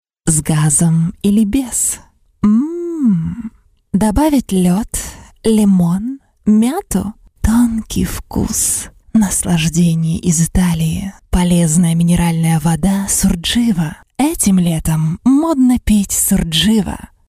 Sprecherin russisch ukrainisch.
Sprechprobe: eLearning (Muttersprache):